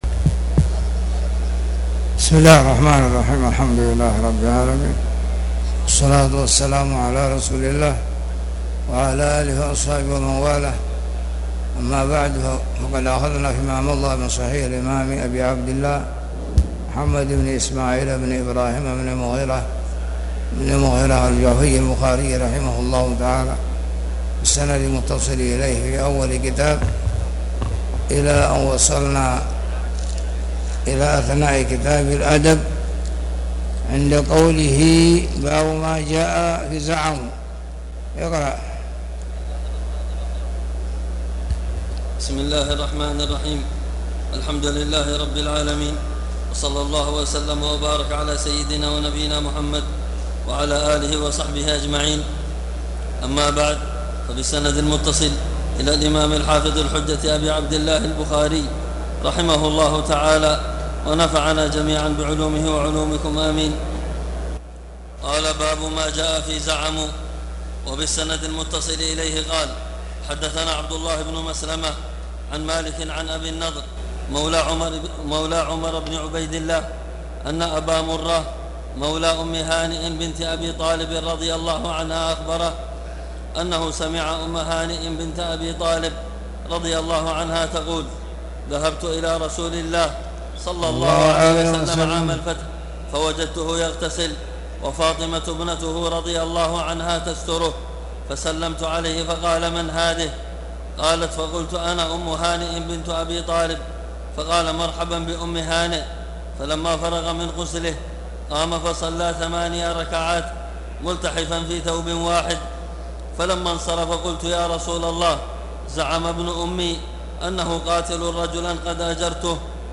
تاريخ النشر ٢٧ جمادى الآخرة ١٤٣٨ هـ المكان: المسجد الحرام الشيخ